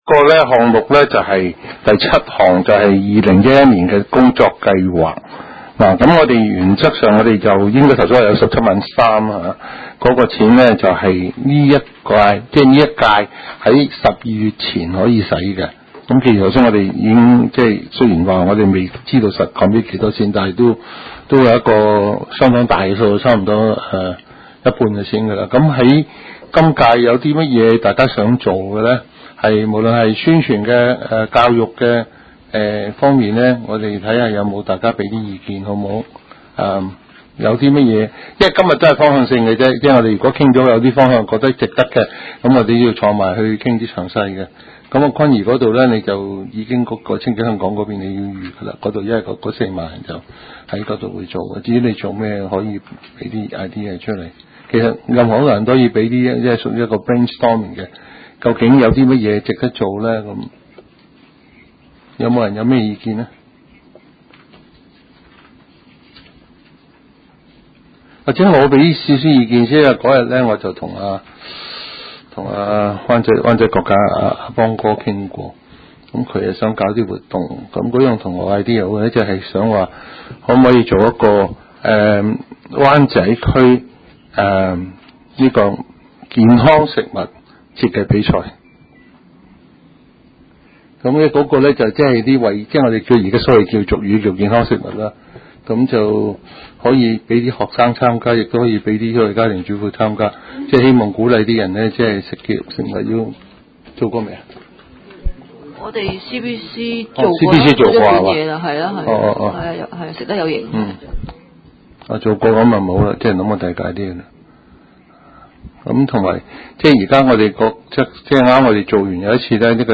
食物及環境衛生委員會第二十次會議
灣仔民政事務處區議會會議室